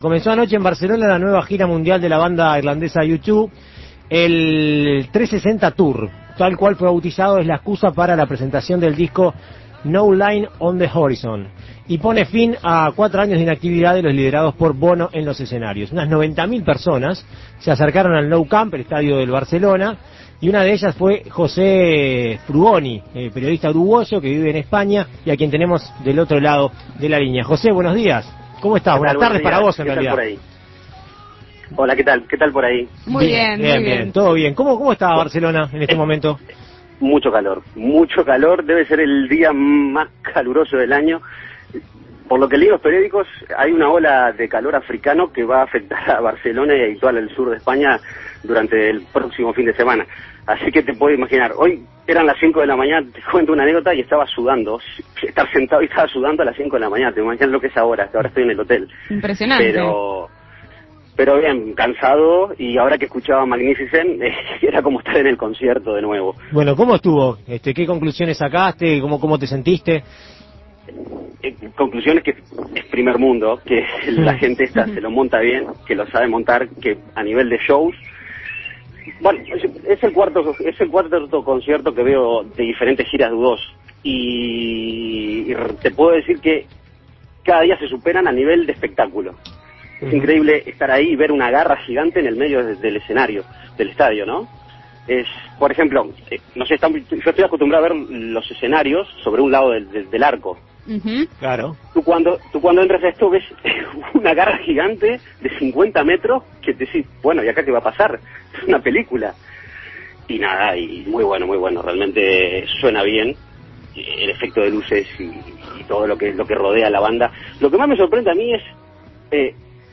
periodista uruguayo radicado en España, estuvo allí y dialogó con la Segunda Mañana.